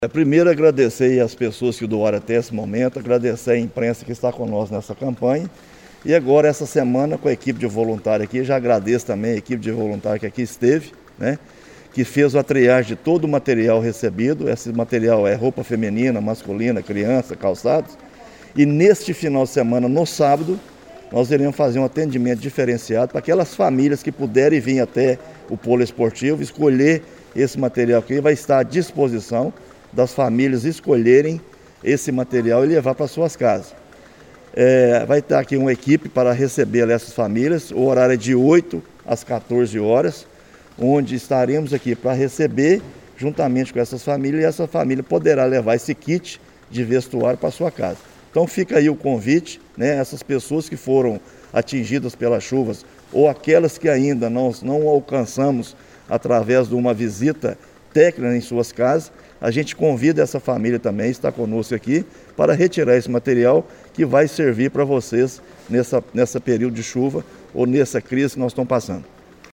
Neste sábado, 22 de janeiro, tem início a distribuições das roupas e calçados arrecadados, como destaca o secretário Flávio Medina: